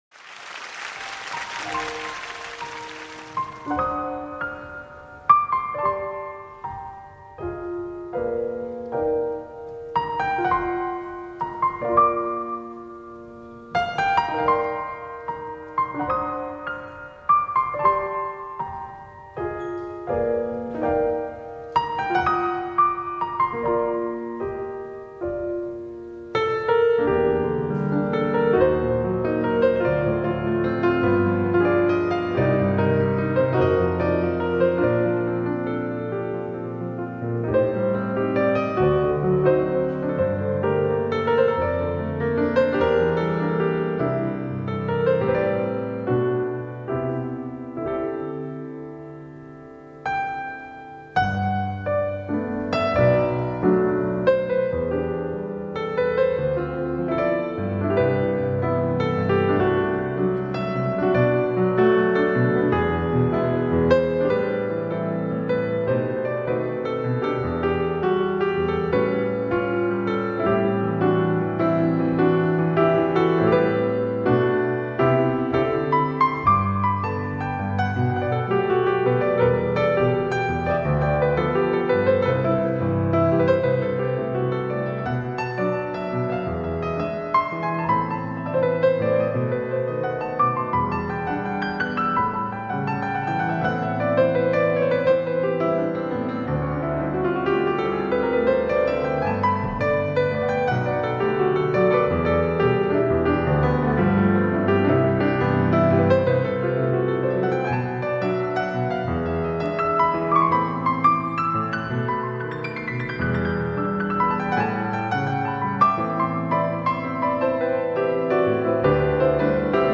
시간 관계상 줄인건지 몰라도 편곡 멋지네요.